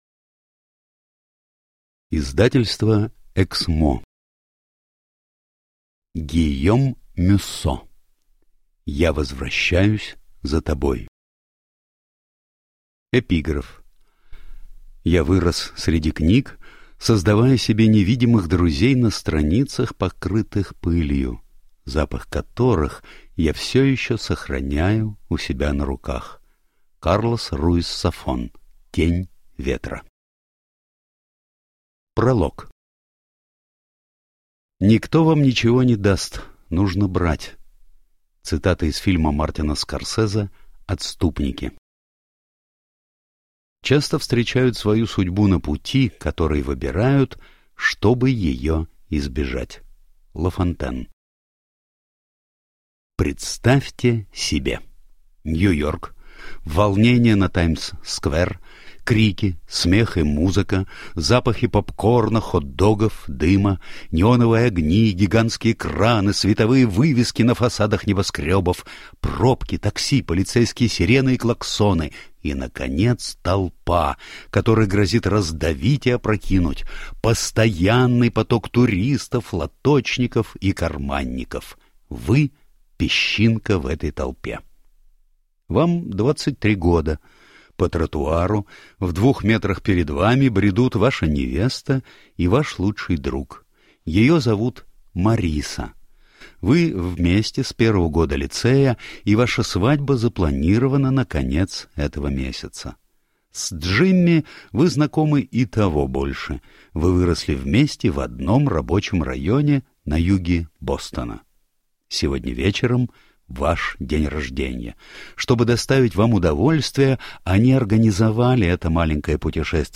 Аудиокнига Я возвращаюсь за тобой | Библиотека аудиокниг